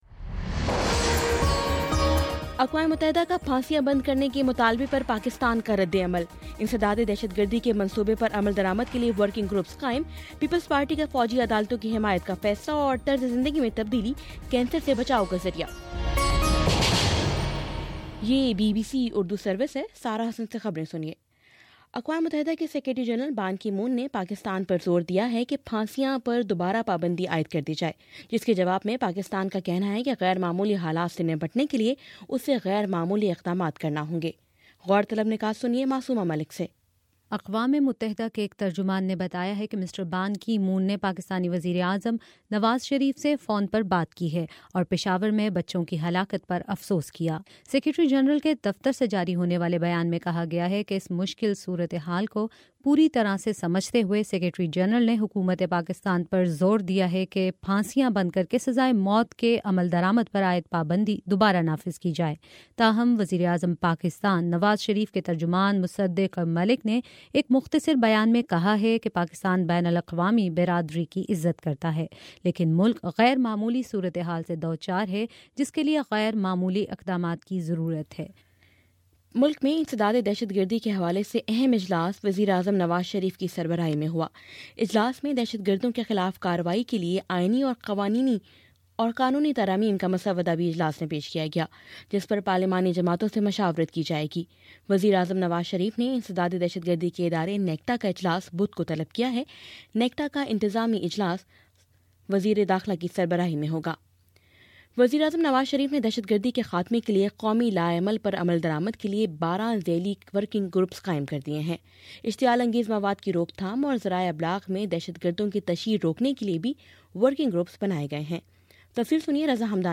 دسمبر 27 : شام چھ بجے کا نیوز بُلیٹن